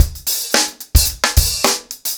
TupidCow-110BPM.1.wav